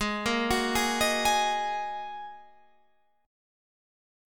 G#7sus2 chord